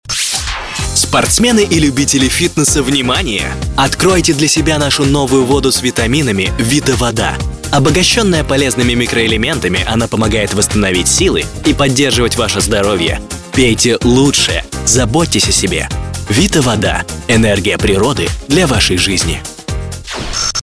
Муж, Рекламный ролик/Молодой